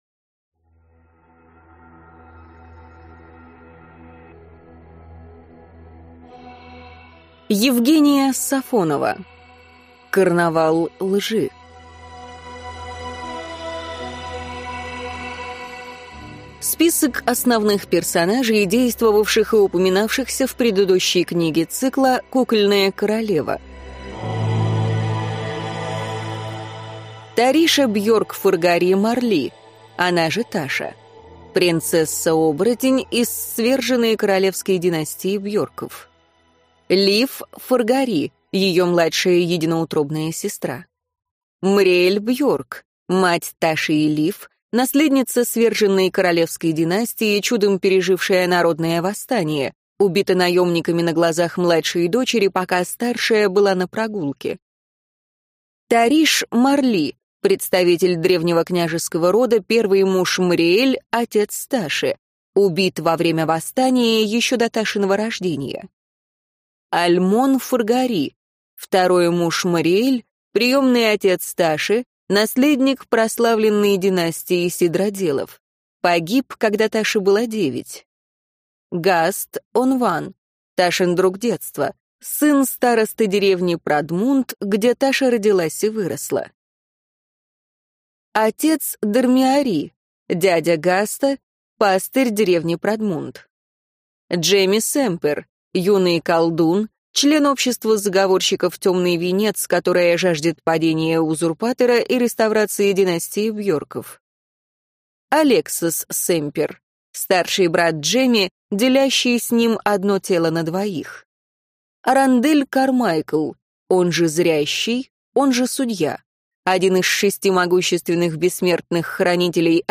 Аудиокнига Карнавал лжи | Библиотека аудиокниг